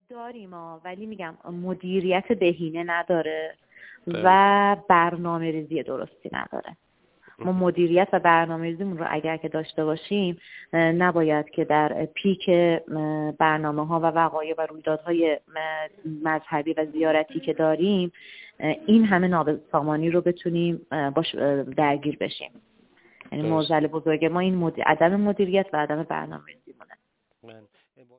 مدرس گردشگری: